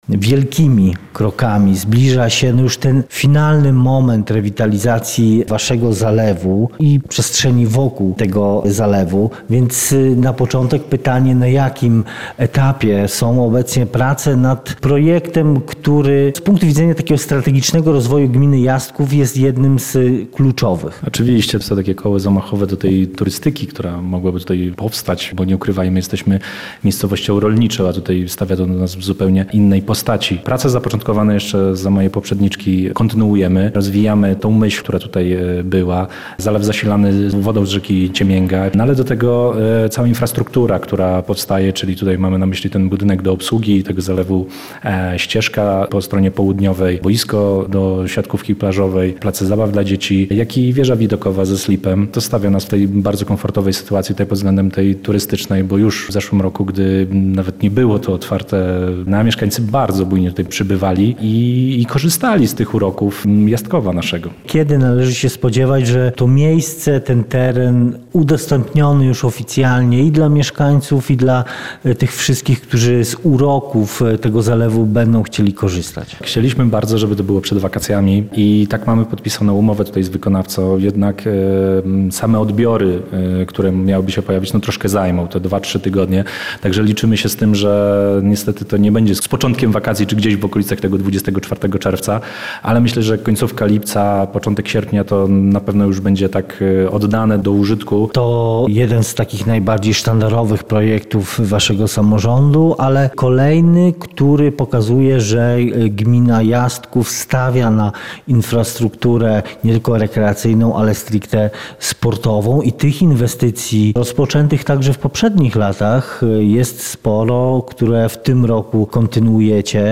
Rozmowa z wójtem gminy Jastków Pawłem Jędrejkiem